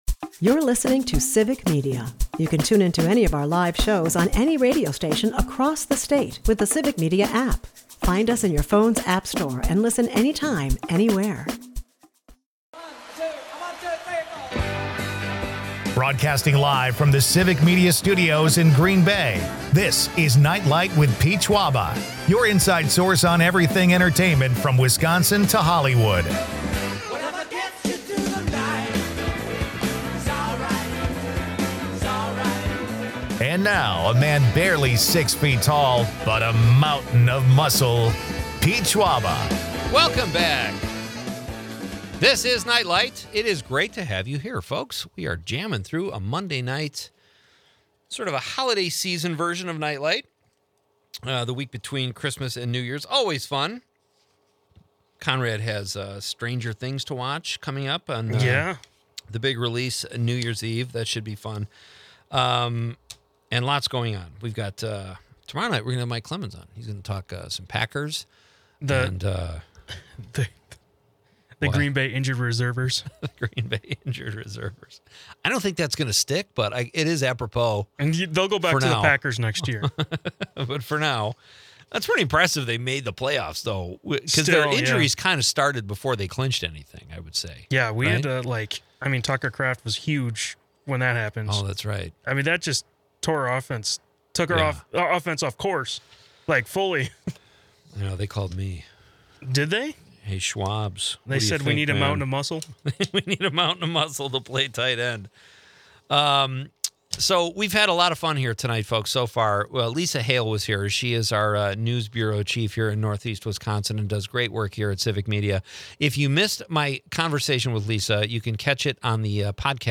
Dive into Wisconsin’s entertainment scene with a nod to the iconic cheese drop in Plymouth, where Sartori's giant wedge takes center stage. As they discuss top card games like Texas Hold'em and cribbage, listeners are invited to share their favorites. With guests spanning from comedians to community leaders, this episode is a vibrant blend of laughter, local culture, and the unique traditions that make Wisconsin shine.